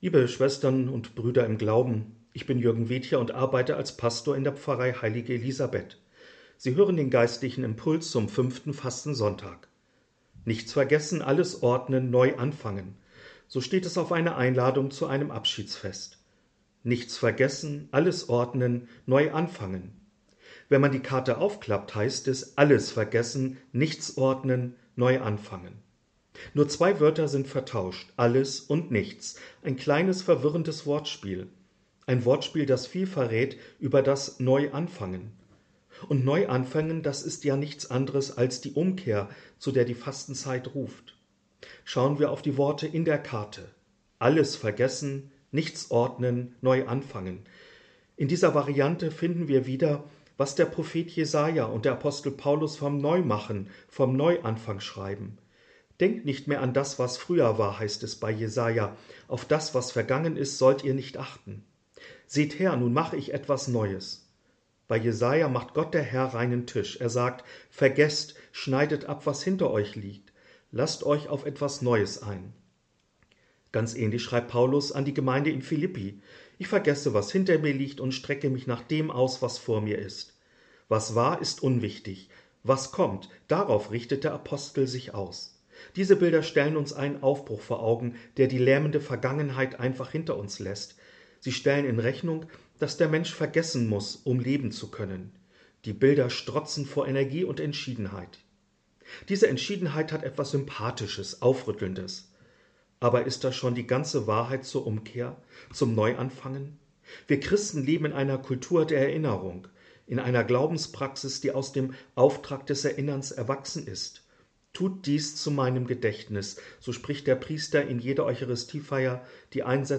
Sie hören den geistlichen Impuls zum 5. Fastensonntag.